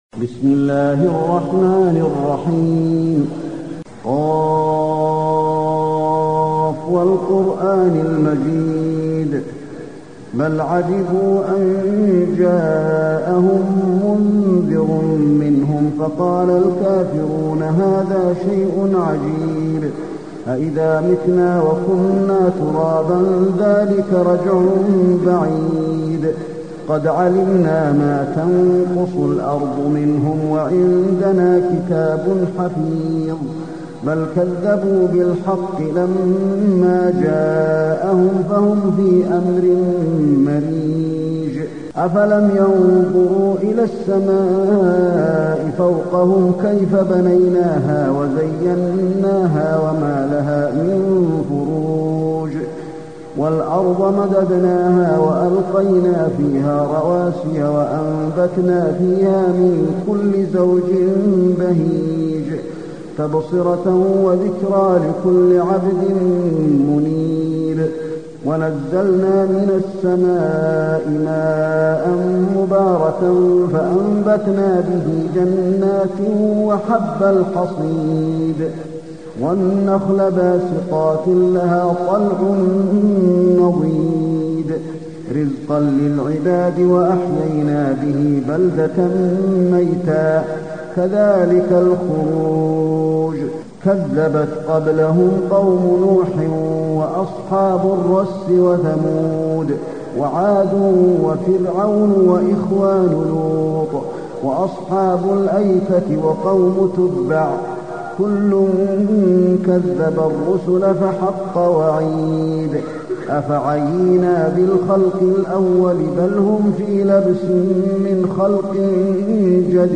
المكان: المسجد النبوي ق